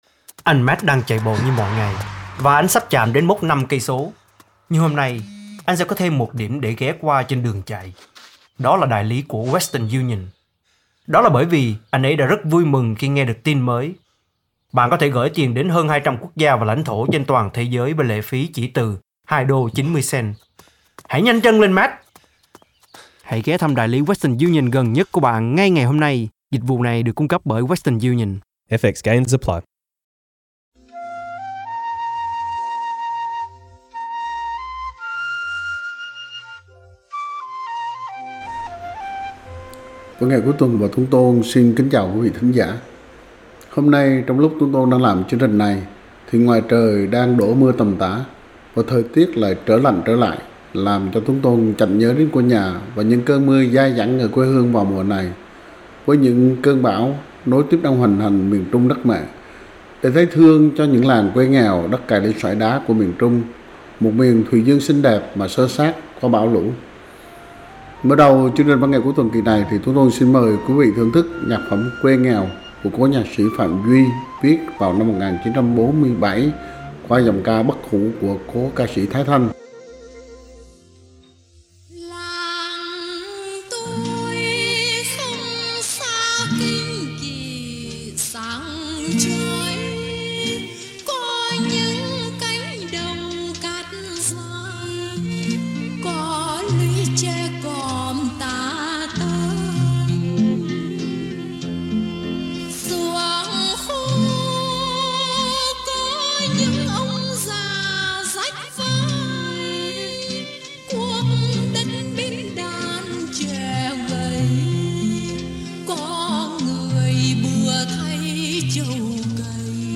Hình ảnh những làng quê nghèo khó, ngập tràn nắng gió và cảnh đẹp đến nao lòng của miền thuỳ dương Việt nam đã được các nhạc sĩ khắc hoạ qua những bản nhạc nhẹ nhàng mà sâu lắng, mơ màng đầy cảm xúc.